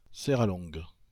Serralongue (French pronunciation: [sɛʁalɔ̃ɡ]